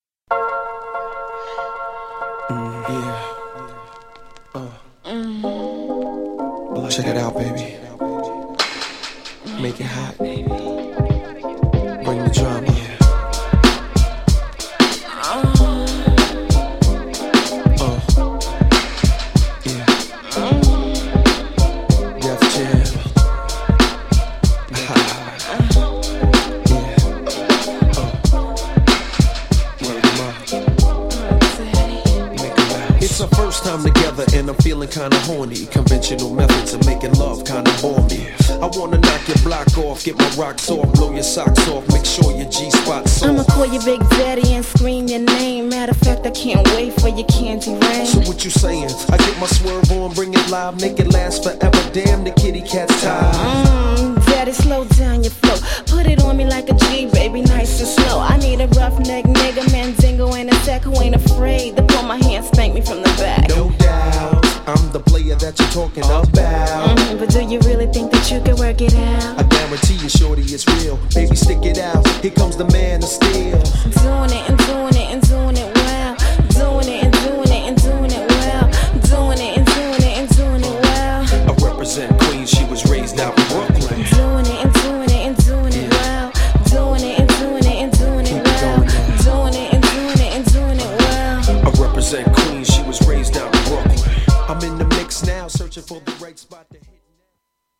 GENRE Hip Hop
BPM 81〜85BPM
SMOOTH_&_MELLOW # SMOOTH_HIPHOP
フックでR&Bなコーラスがイイ! # メロウHIPHOP